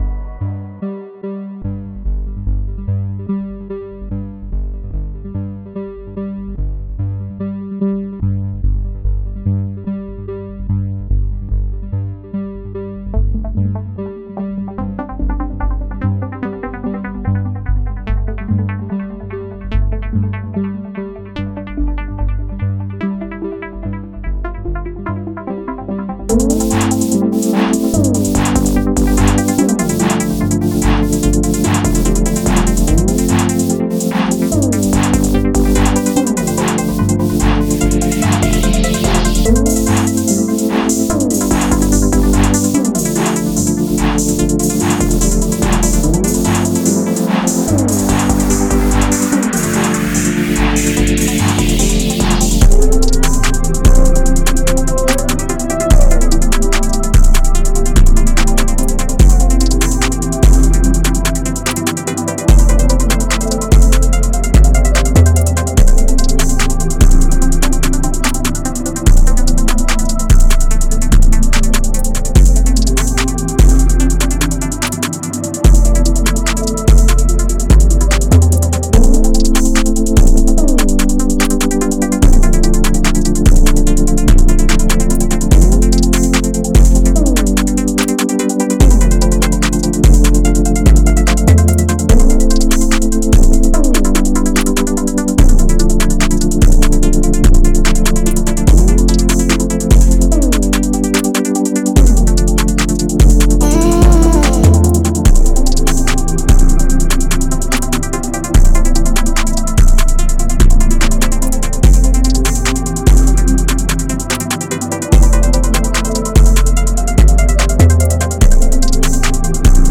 The arrangement is restrained, but intentional.